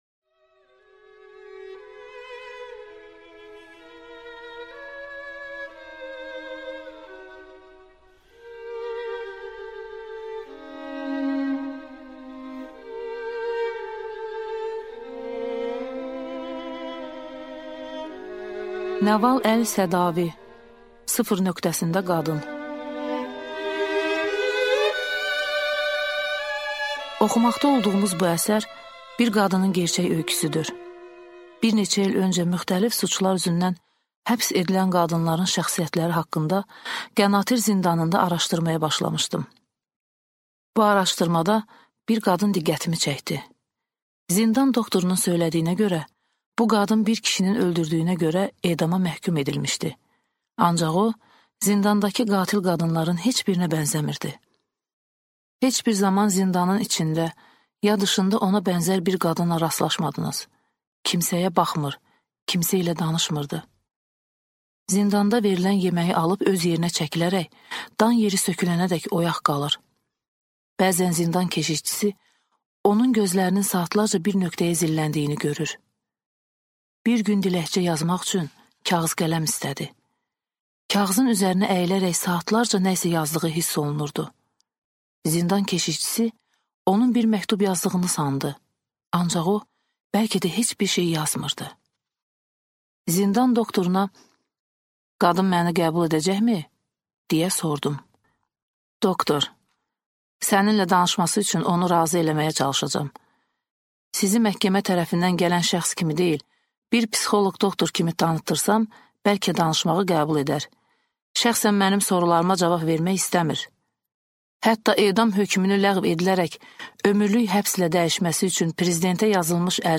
Аудиокнига Sıfır nöqtəsində qadın | Библиотека аудиокниг